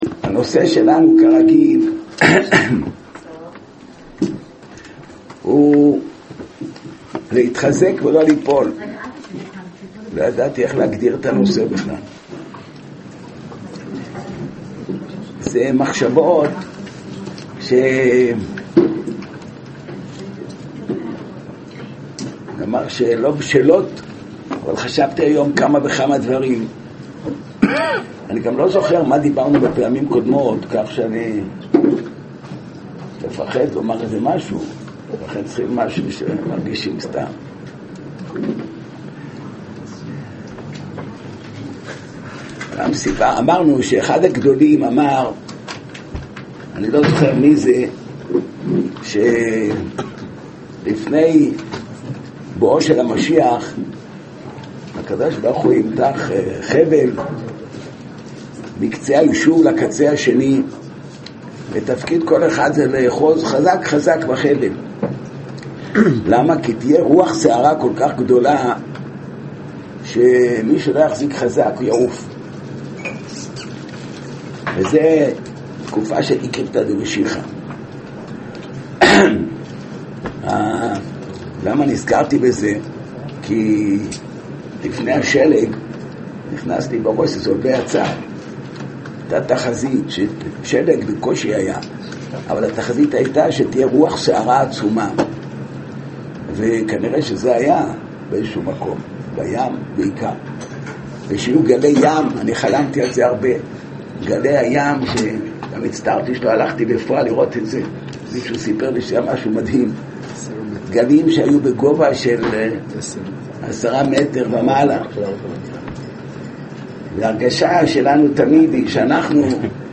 מאגר שיעורים תורני